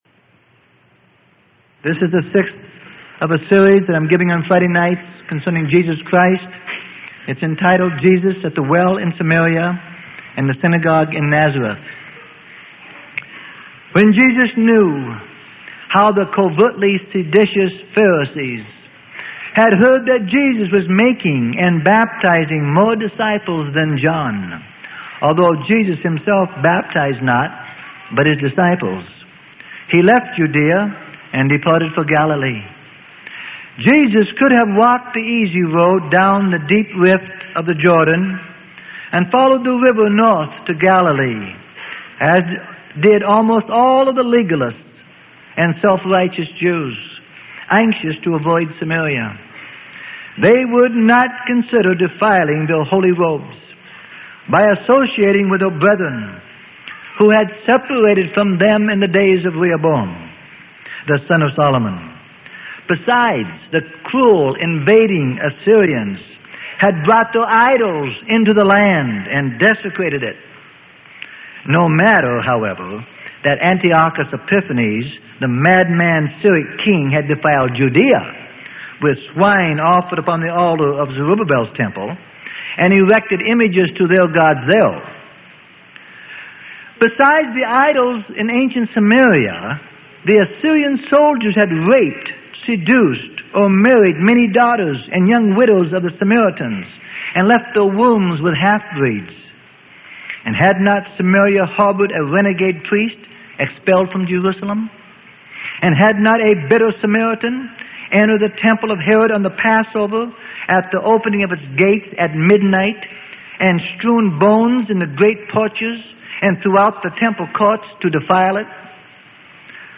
Sermon: The Life Of Jesus.